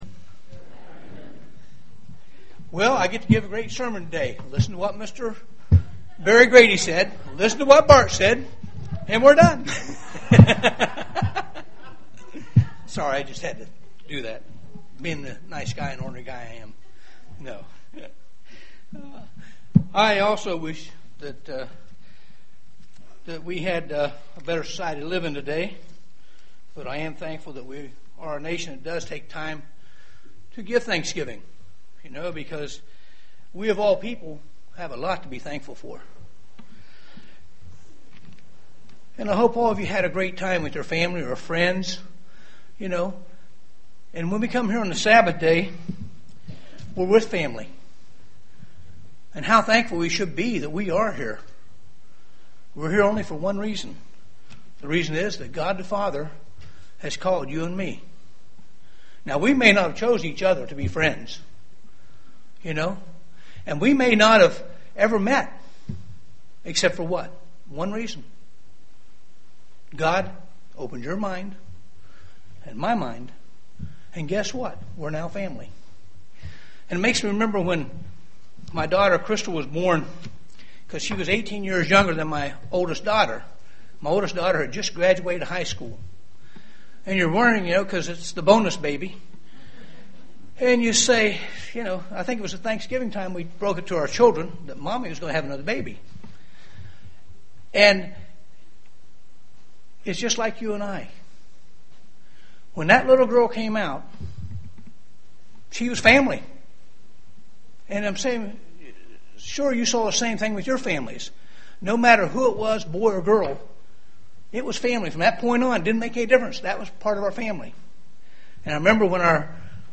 Print God Commands us to take care of the Widows UCG Sermon Studying the bible?
Given in Dayton, OH